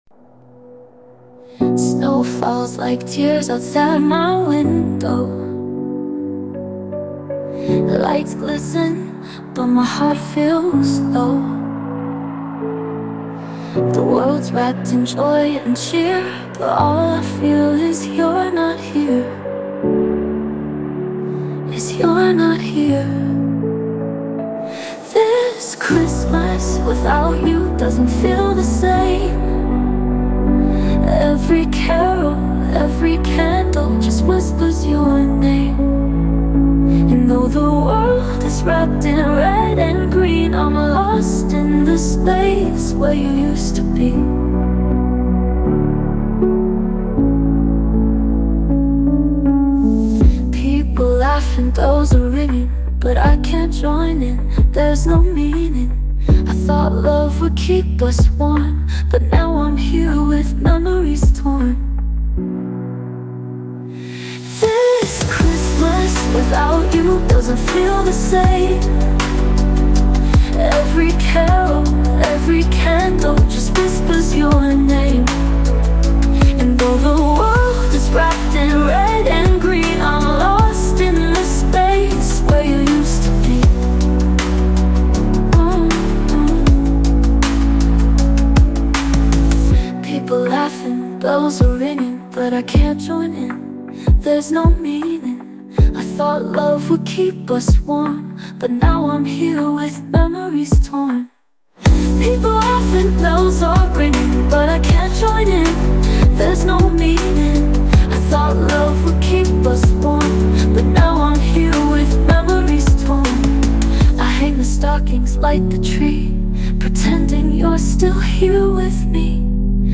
heartfelt holiday song